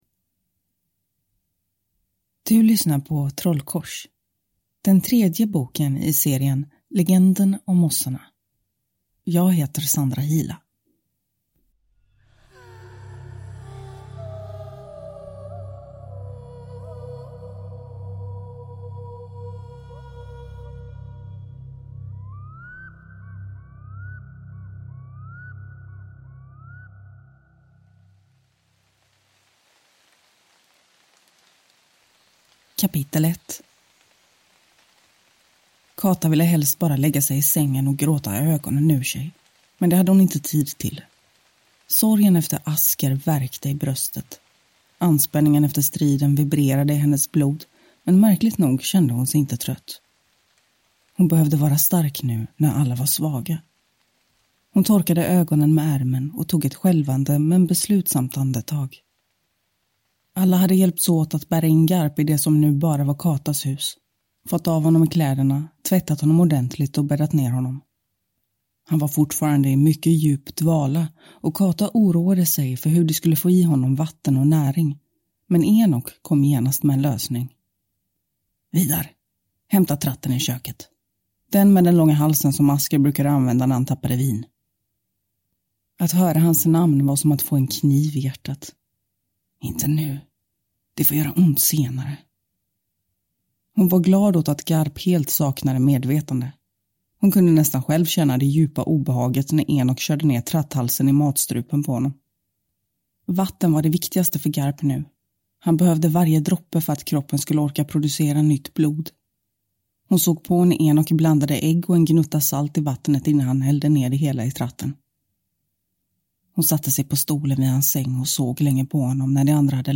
Trollkors – Ljudbok